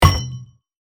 Sports, Athletics, Game Menu, Ui Confirm Sound Effect Download | Gfx Sounds
Sports-athletics-game-menu-ui-confirm.mp3